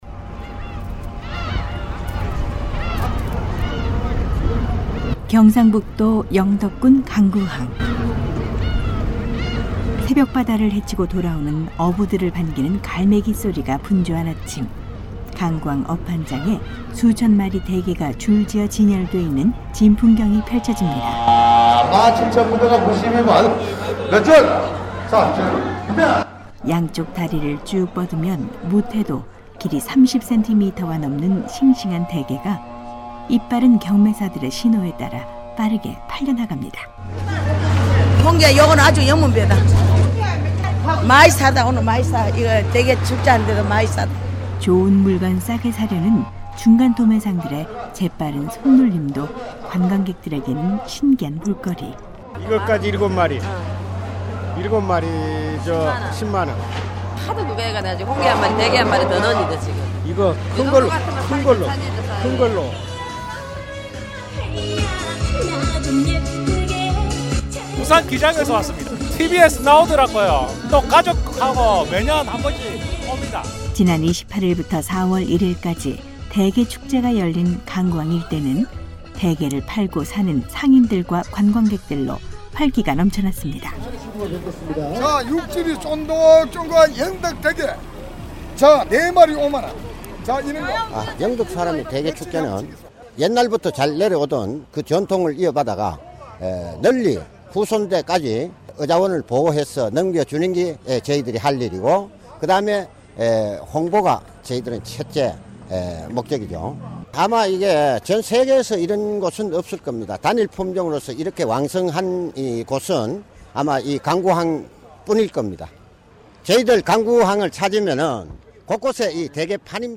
한국사회의 이모저모를 알아보는 ‘안녕하세요 서울입니다’ 오늘은 경상북도 영덕에서 열린 대게 축제 현장 소식입니다.